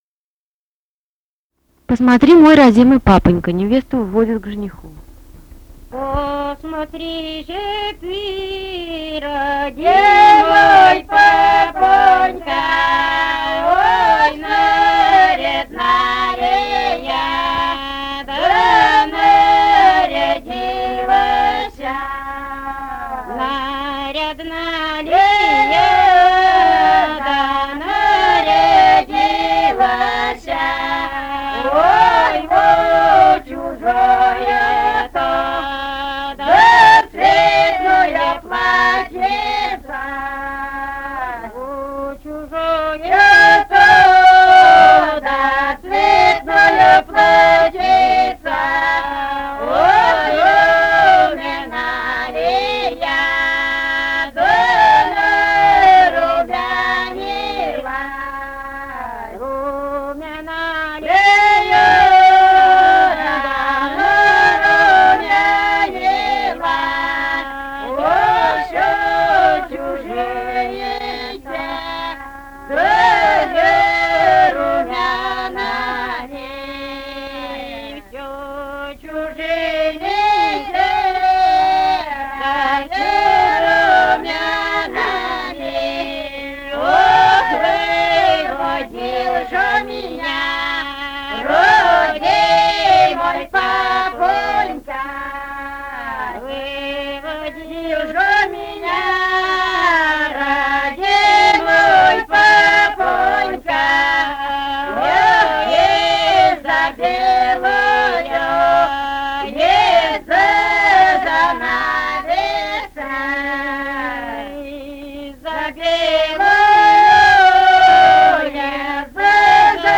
полевые материалы
Пермский край, д. Меньшиково Очёрского района, 1968 г. И1073-03